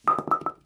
bowlingPinFall_1.wav